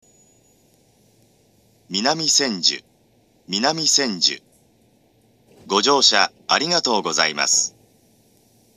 ２０１４年１２月１５日には、２０１５年３月開業の上野東京ラインに対応するため、自動放送の男声が変更されています。
１番線到着放送